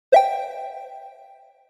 SE（魔法）
ポロン。魔法。アイテムゲット。